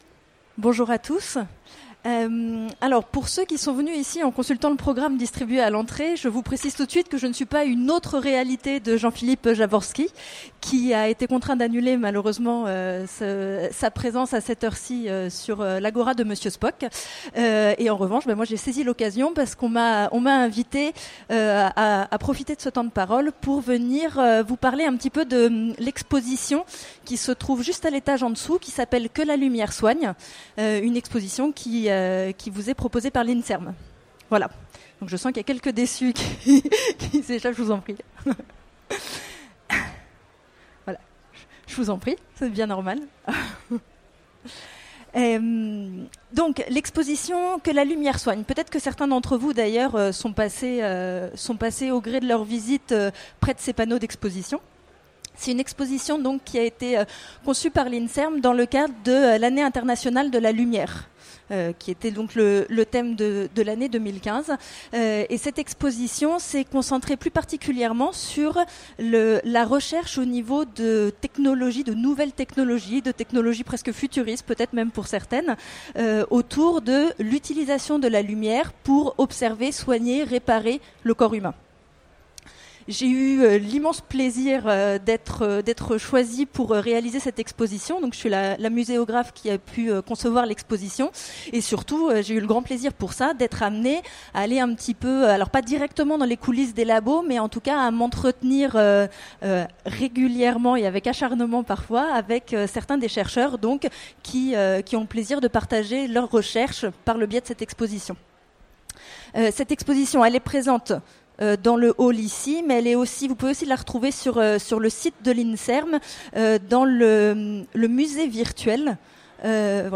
Utopiales 2015 : Conférence Quand la réalité des labos rejoint la science-fiction